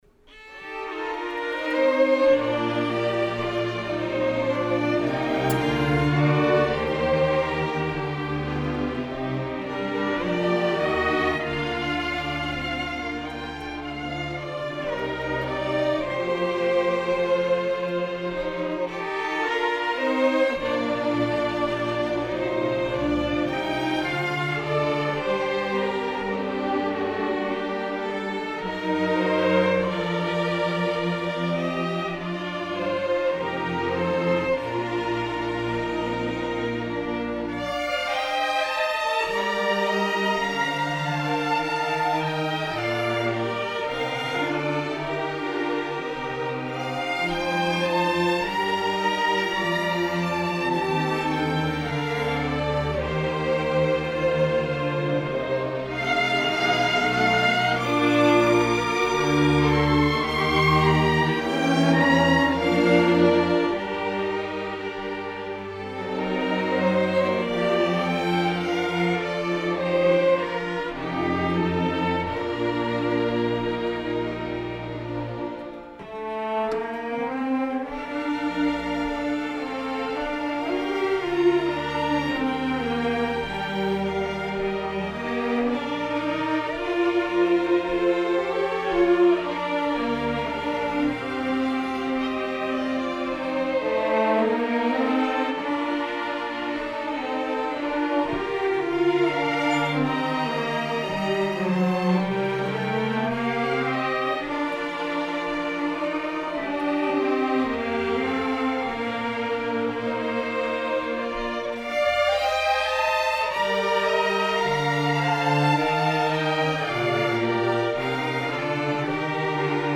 Fall 2016 Concert
Two Folk Songs - Transcribed for String Orchestra by G.F. McKay